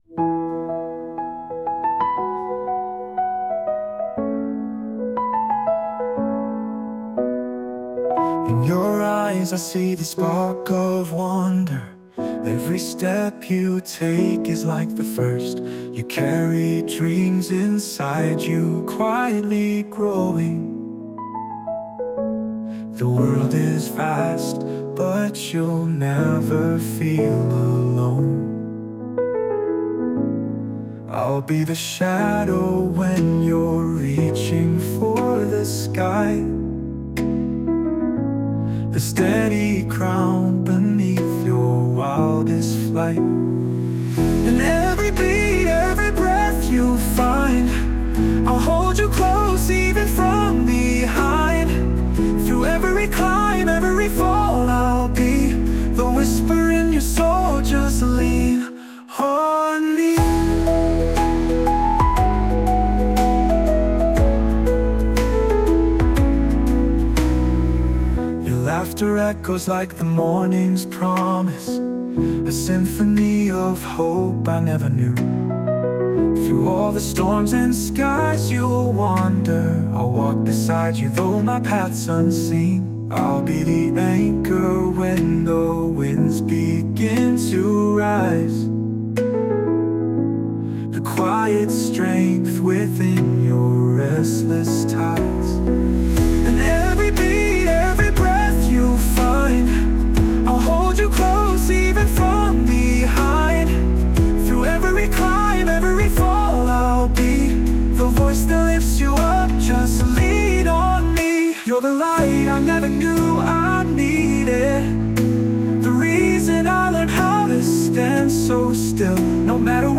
洋楽男性ボーカル著作権フリーBGM ボーカル
著作権フリーオリジナルBGMです。
男性ボーカル（洋楽・英語）曲です。